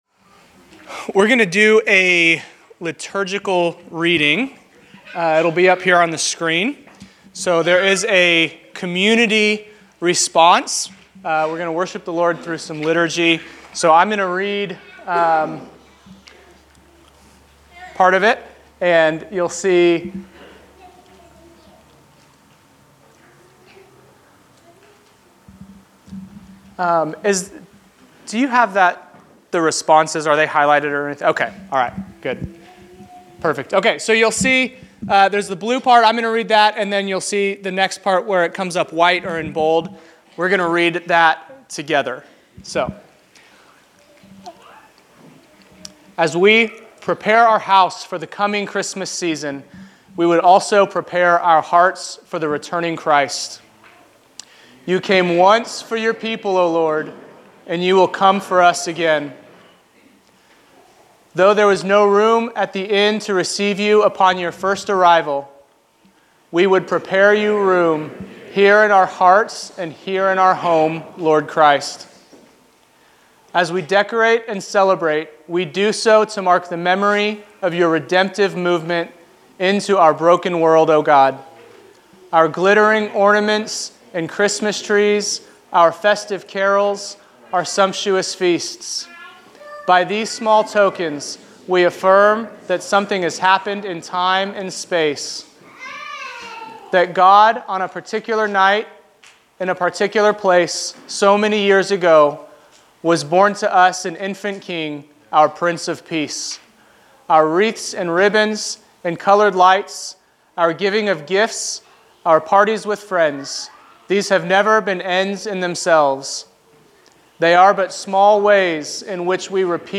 Liturgical Reading
Location: El Dorado